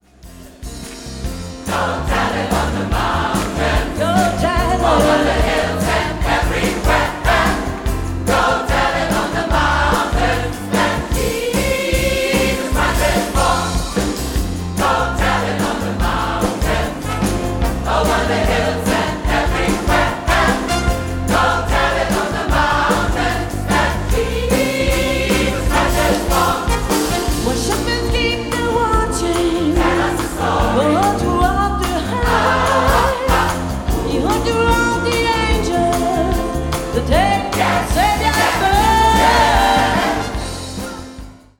Demo
• SAB, Solo + Piano